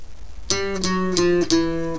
Todos los requintos son para Bajosexto!!